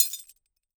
GLASS_Fragment_07_mono.wav